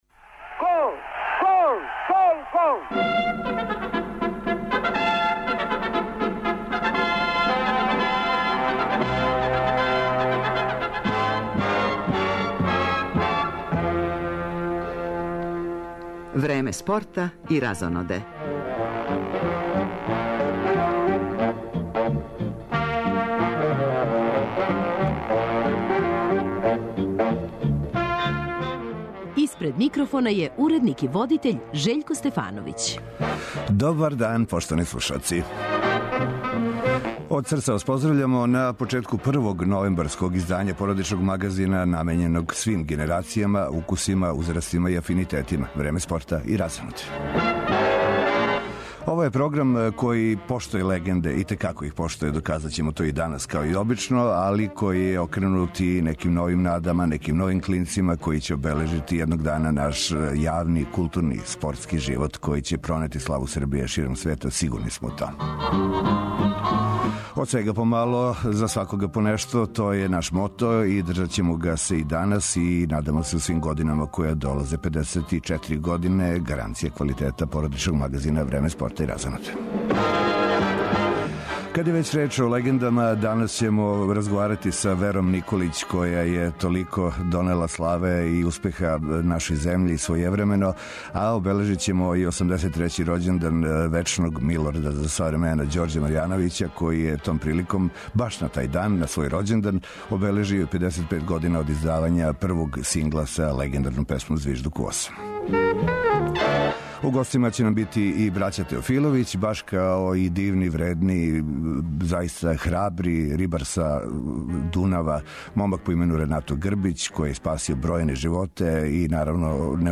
Тони Парсонс, један од највећих и најпродаванијих писаца данашњице и један од најпознатијих британских писаца, гост је овогодишњег Сајма књига у Београду.